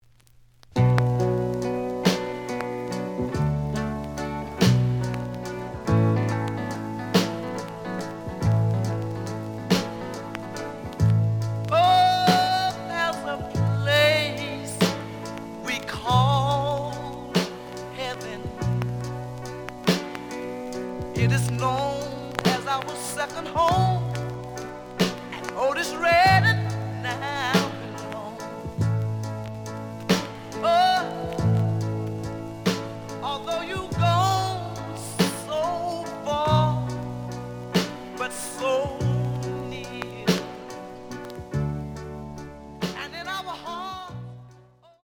The audio sample is recorded from the actual item.
●Genre: Soul, 60's Soul
Some click noise on B side label due to scratches.